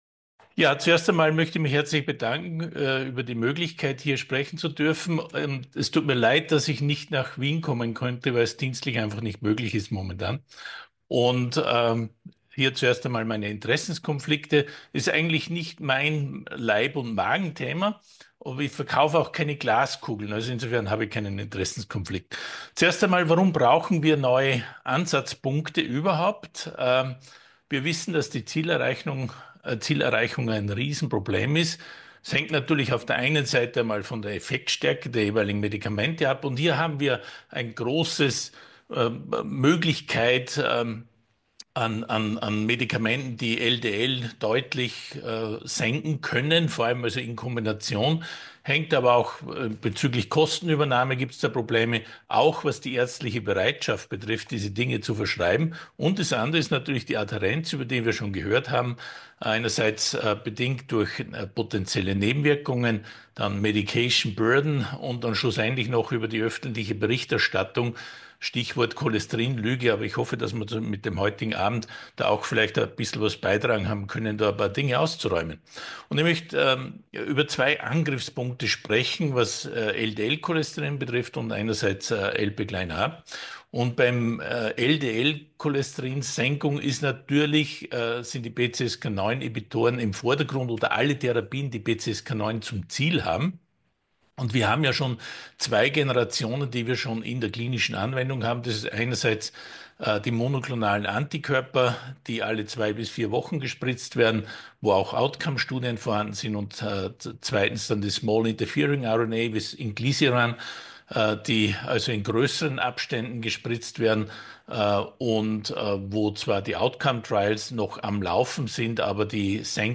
Sie haben den Vortrag noch nicht angesehen oder den Test negativ beendet.
Lange Nacht der Lipide in Kooperation mit der Cholesterinallianz